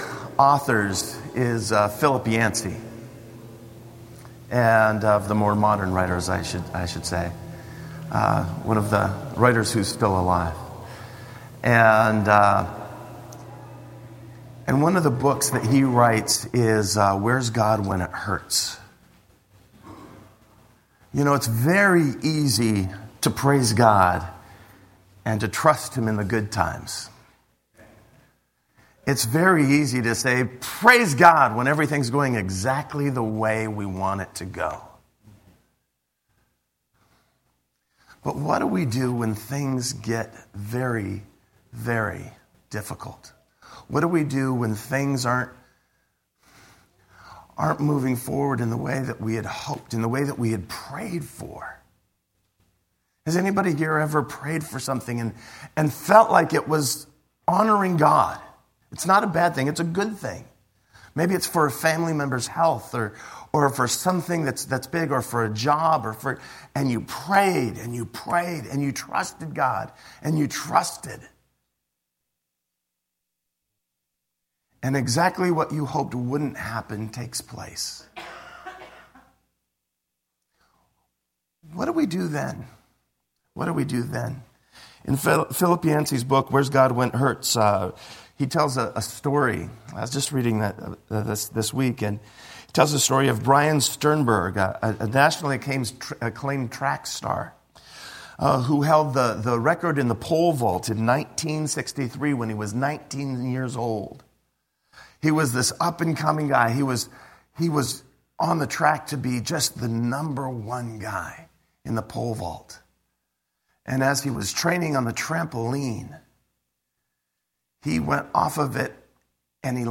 Sermon-3-12.mp3